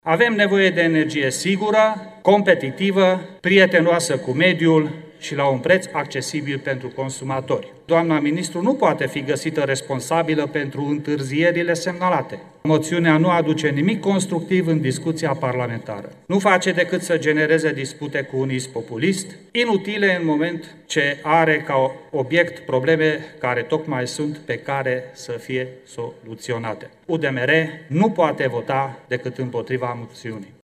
Moțiunea simplă împotriva ministrului Mediului a fost dezbătută în Camera Deputaților.
Bendeș Șandor, deputat UDMR: „UDMR nu poate vota decât împotriva moțiunii”